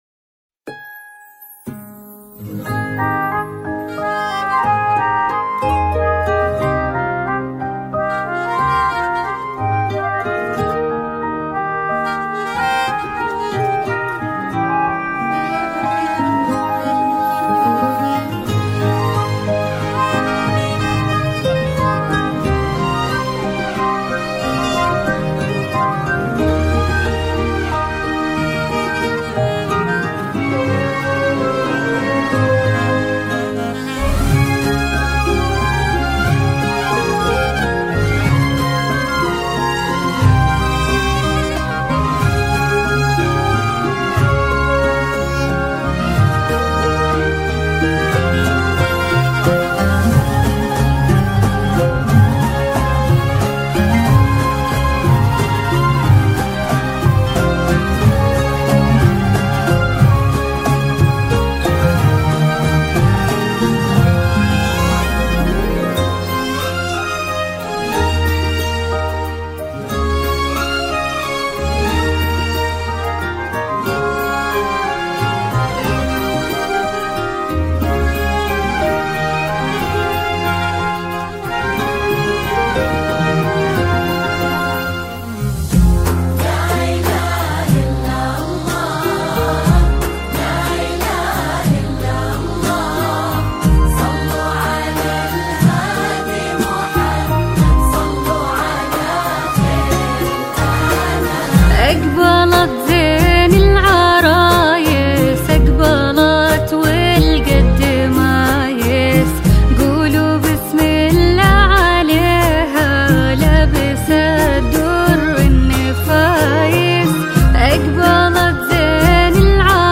alt="زفات سعودية للعروس"